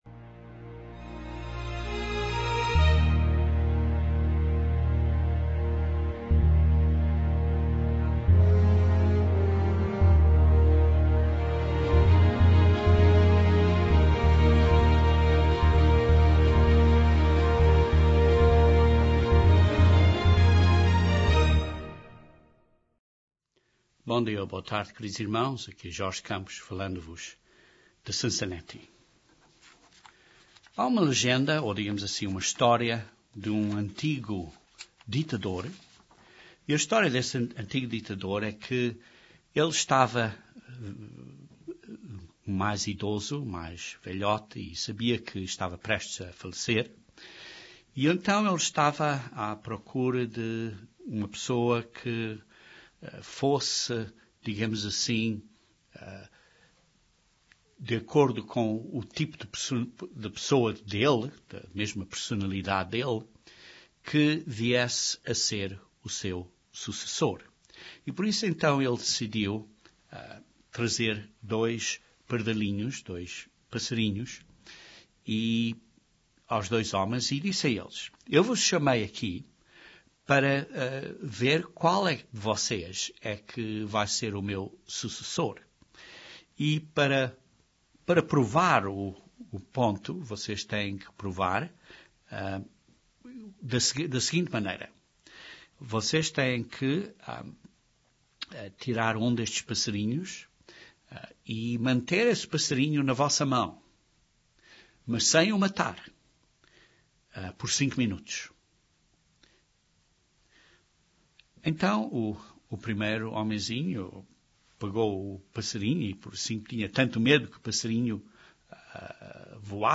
Este sermão aborda estes assuntos.